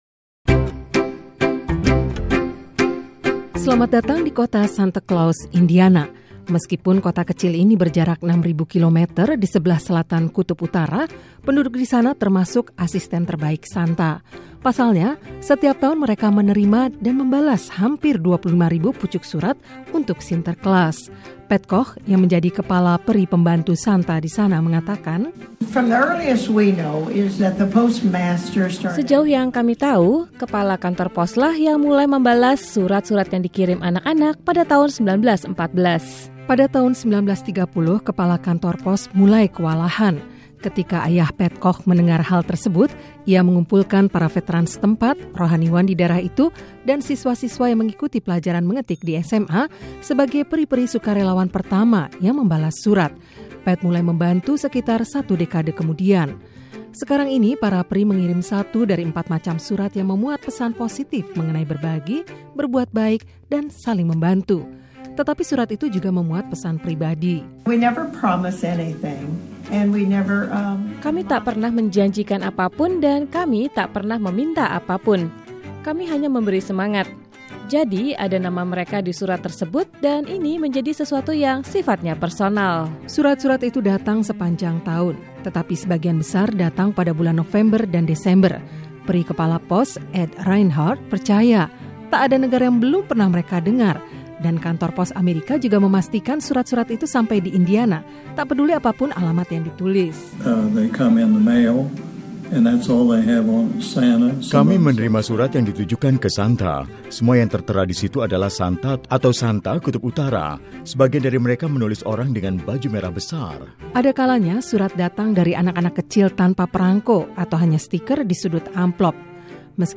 Setiap Natal, anak-anak di seluruh dunia menulis surat untuk Santa Claus atau Sinterklas. Meskipun pesan mereka itu tidak pernah tiba di Kutub Utara, surat-surat itu sampai di tangan Sinterklas, dan dibalas. Berikut laporan selengkapnya